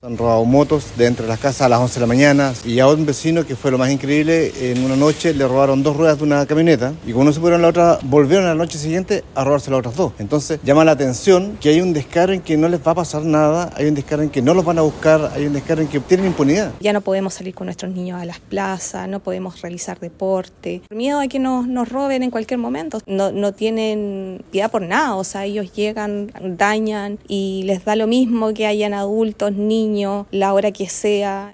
Tras este violento hecho, vecinos del sector Pucará de Andalué indicaron a Radio Bío Bío que se han registrado diversos robos y que los delincuentes actúan con total impunidad.